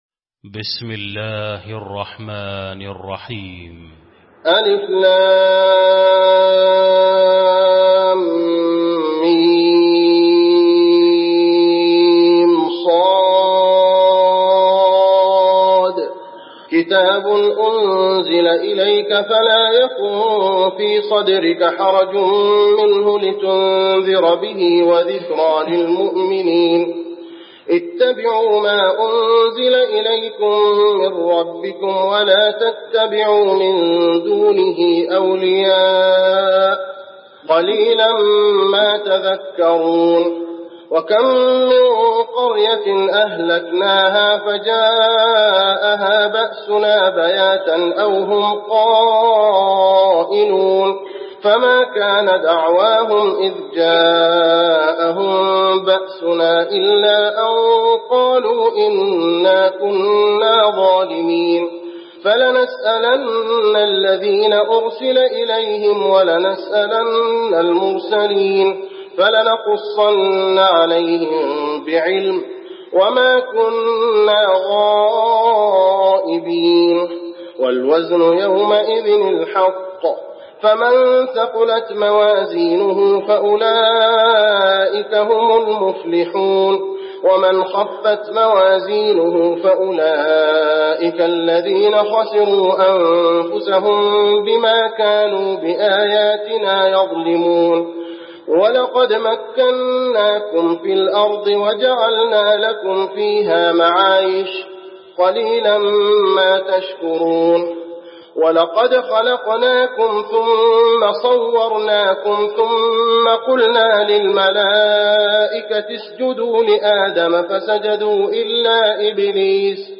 المكان: المسجد النبوي الأعراف